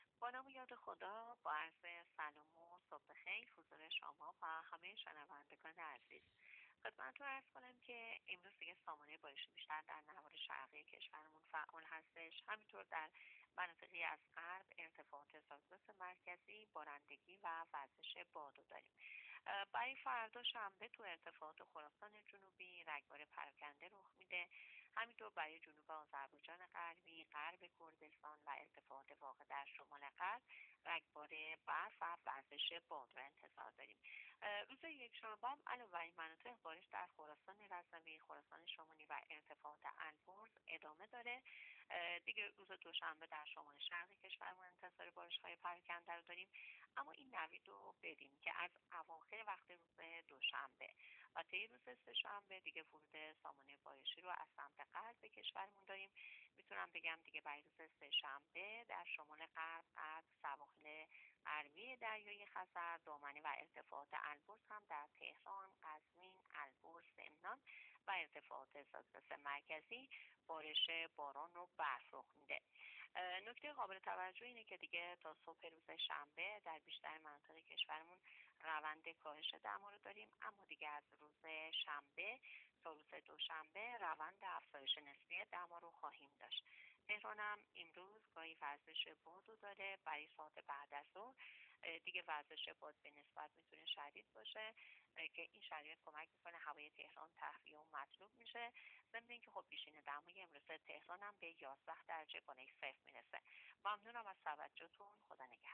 گزارش رادیو اینترنتی از آخرین وضعیت آب و هوای دهم بهمن؛